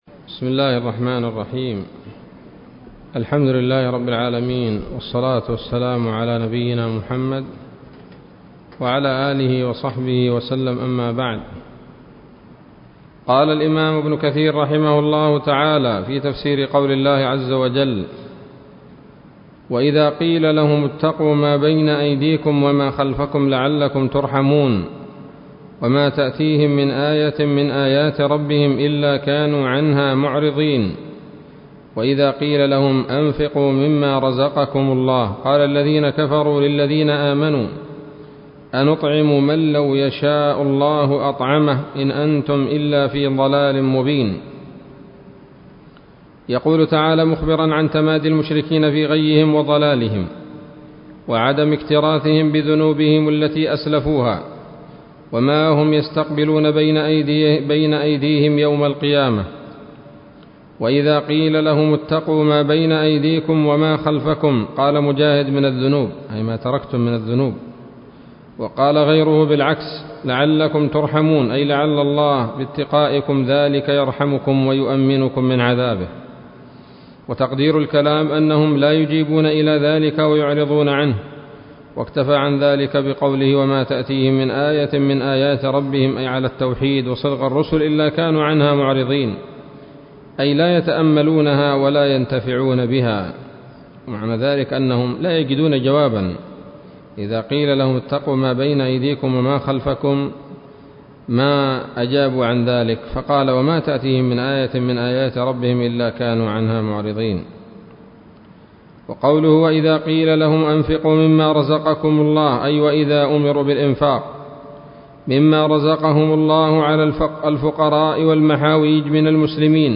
الدرس العاشر من سورة يس من تفسير ابن كثير رحمه الله تعالى